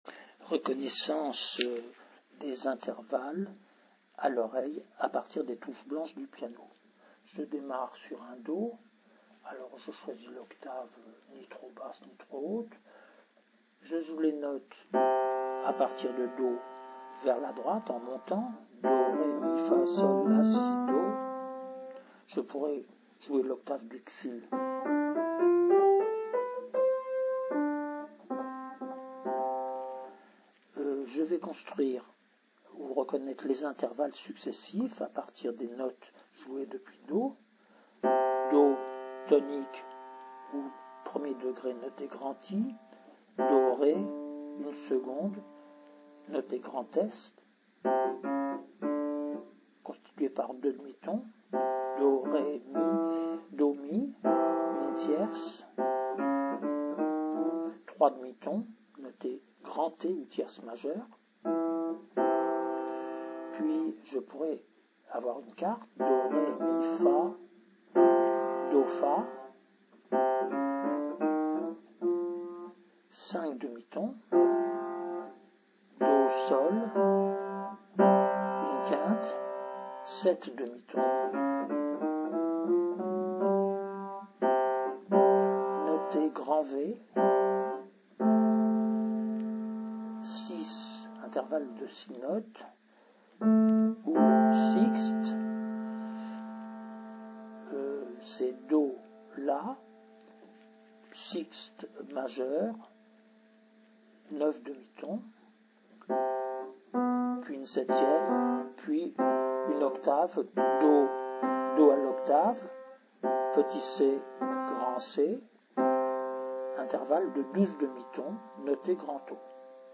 Exercice 1 : Reconnaissance des intervalles au piano